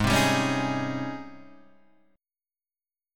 G# Major 11th